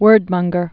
(wûrdmŭnggər, -mŏng-)